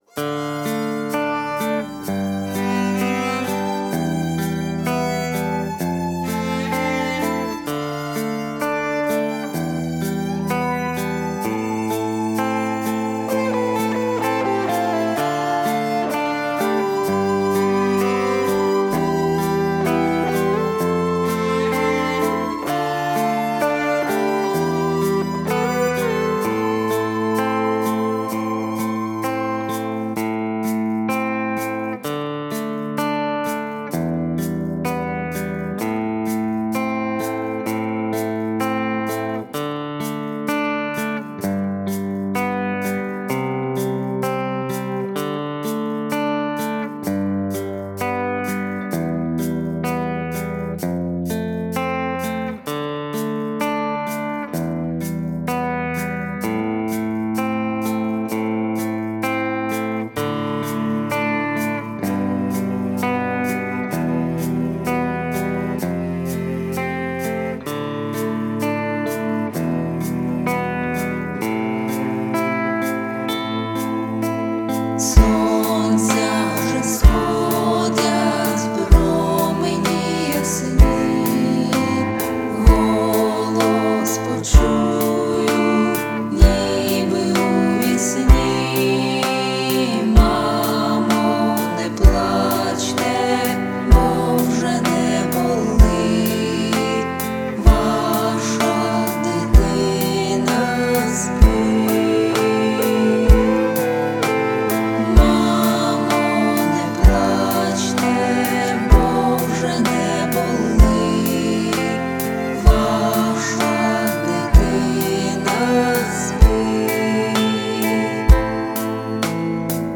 Багато гітари)